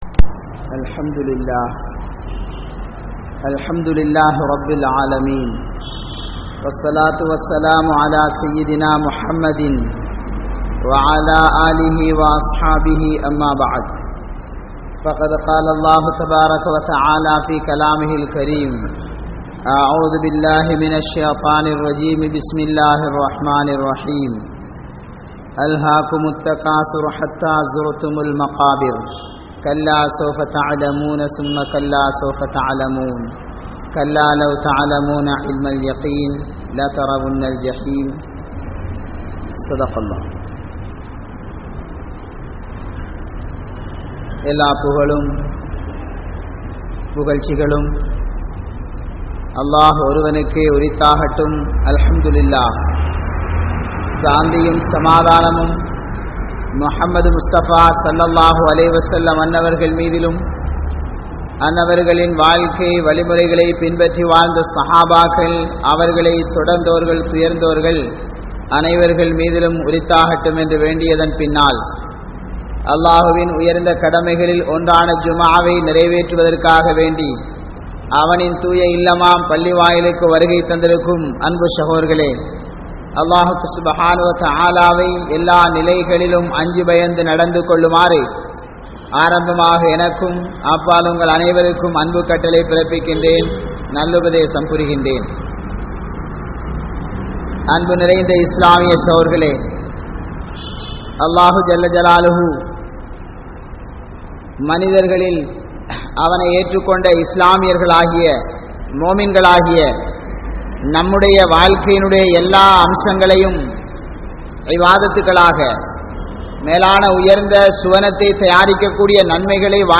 Islam Koorum Kodukkal Vaangal (இஸ்லாம் கூறும் கொடுக்கல் வாங்கல்) | Audio Bayans | All Ceylon Muslim Youth Community | Addalaichenai